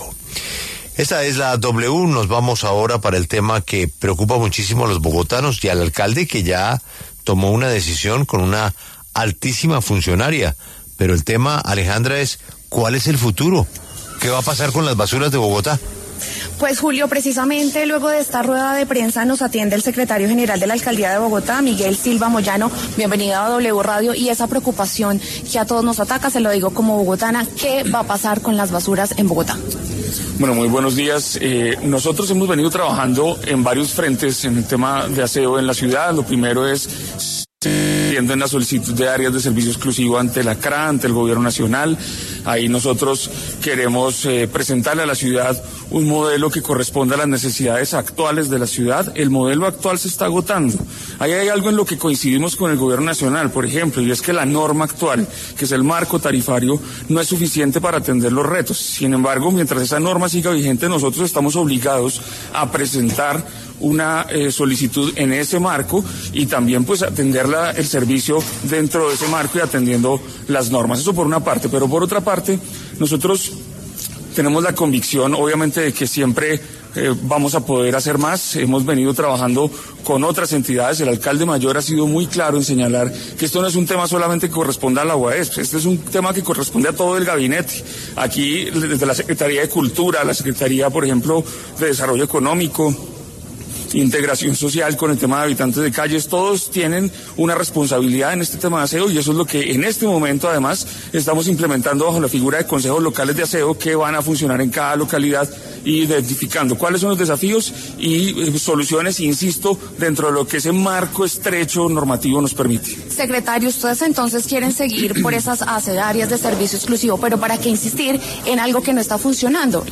El secretario general de la Alcaldía, Miguel Silva, aseguró en La W que la Administración Distrital va a garantizar la prestación del servicio de aseo en Bogotá.